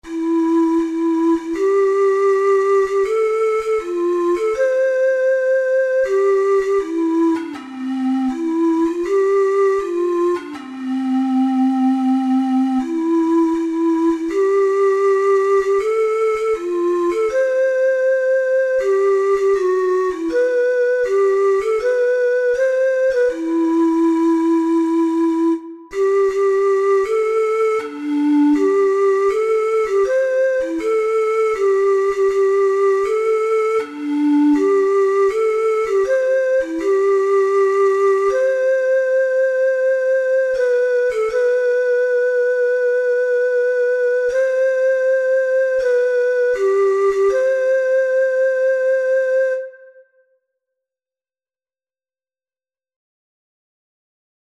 Christmas Christmas Ocarina - Six Hole Sheet Music O Holy Night
Free Sheet music for Ocarina - Six Hole
C major (Sounding Pitch) (View more C major Music for Ocarina - Six Hole )
4/4 (View more 4/4 Music)
Traditional (View more Traditional Ocarina - Six Hole Music)